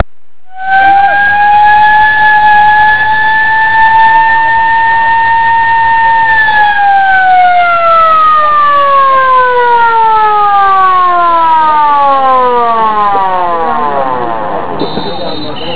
تفضلو يا اخوان اهازيج للنادي الاهلي: